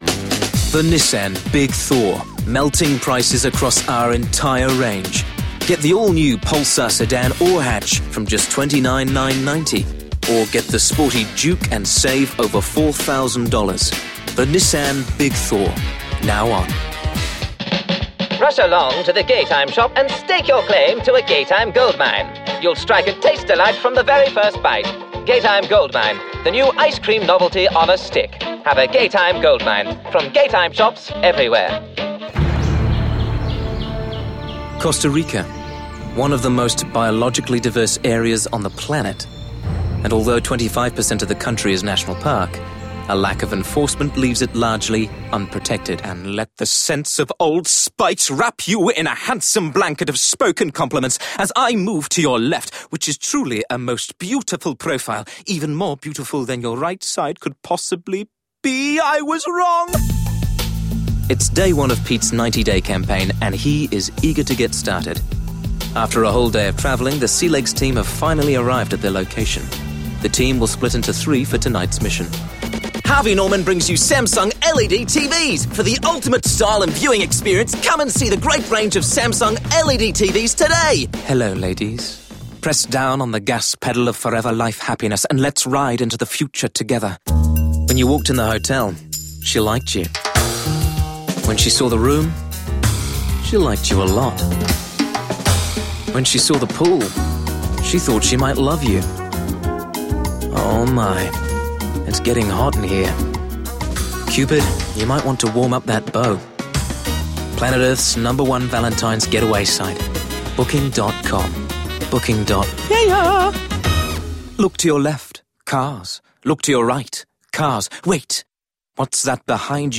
Demo
Adult
Has Own Studio
british rp | natural
new zealand | natural